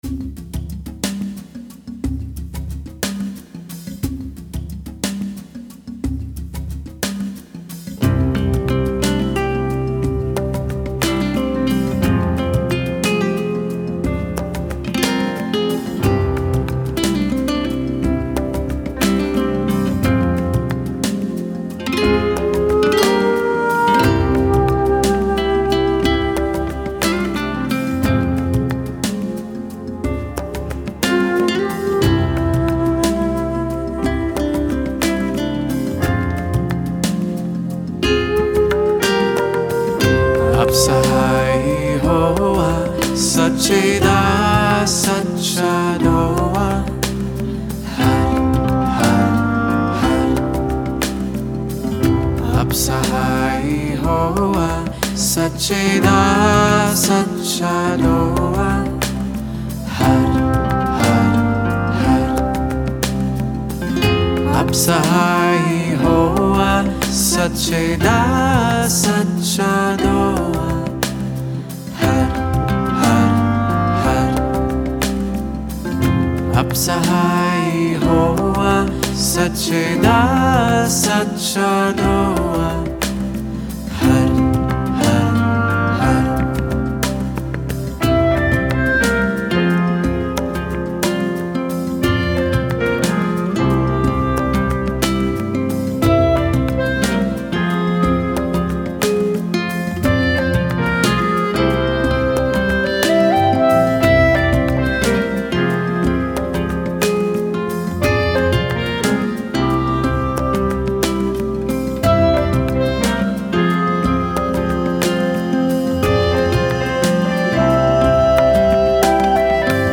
Áudio do Mantra: